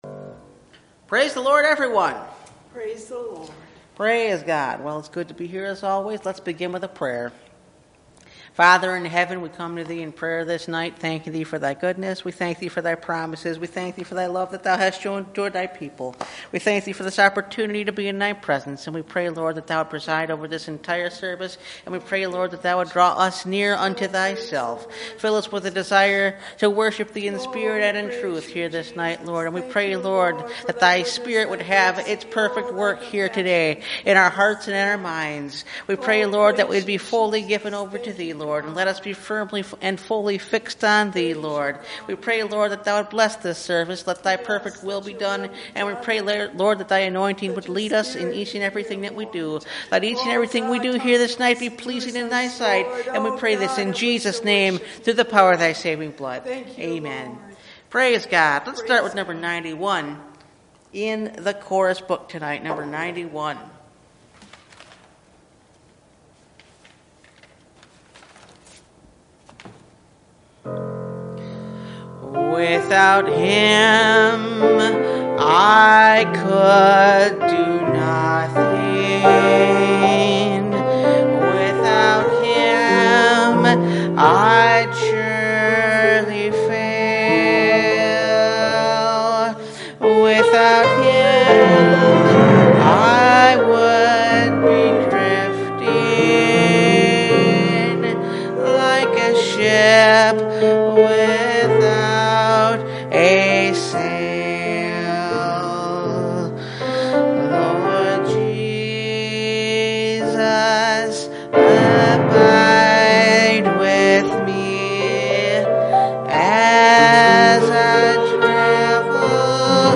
Revelation 20 – Part 1 – Last Trumpet Ministries – Truth Tabernacle – Sermon Library